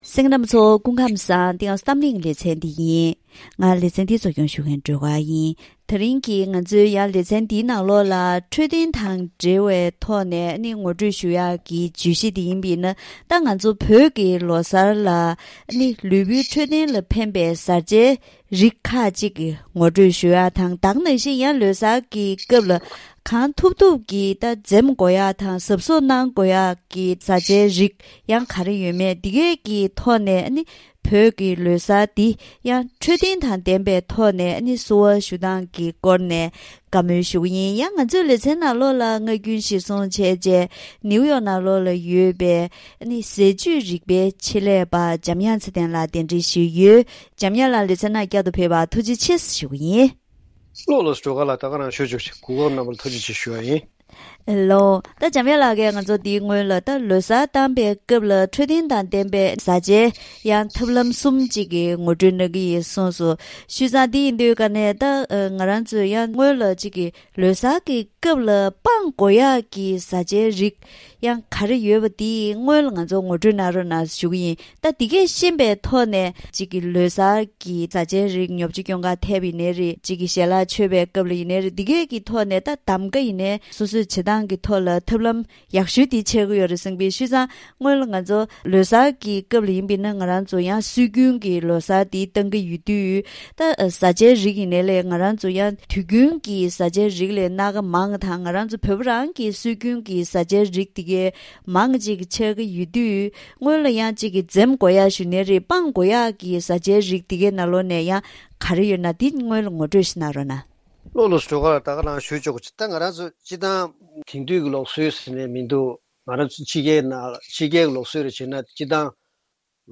ད་རིང་གི་གཏམ་གླེང་ལེ་ཚན་ང་ཚོ་བོད་ཀྱི་ལོ་གསར་སྐབས་ལ་ལུས་པོའི་འཕྲོད་བསྟེན་ལ་ཕན་པའི་ཟས་རིགས་ཁག་ཅིག་ངོ་སྤྲོད་ཞུ་རྒྱུ་དང་། དེ་བཞིན་ལོ་གསར་སྐབས་གང་ཐུབ་ངང་འཛེམ་དགོས་པ་དང་དོ་སྣང་གནང་དགོས་པའི་ཟས་རིགས་ཁག་ངོ་སྤྲོད་ཞུས་པ་ཞིག་གསན་རོགས་གནང་།